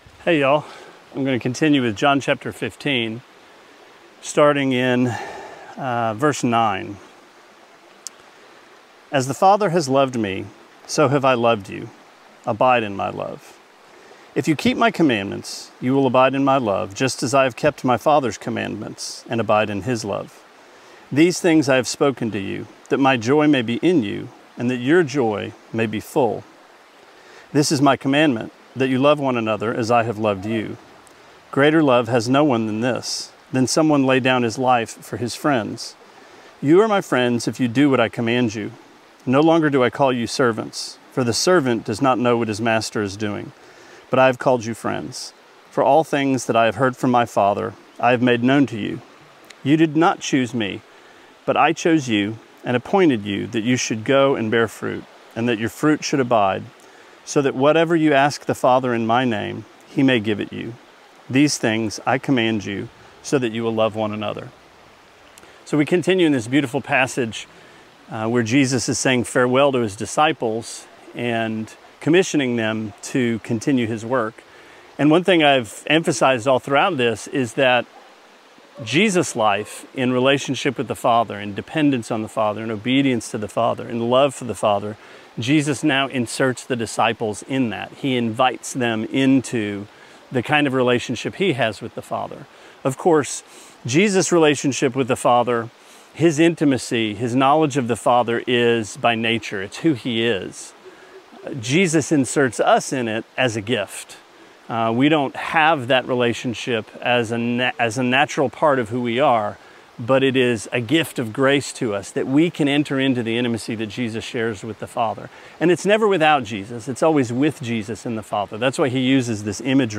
Sermonette 5/25: John 15:9-17: Friends